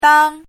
chinese-voice - 汉字语音库
dang1.mp3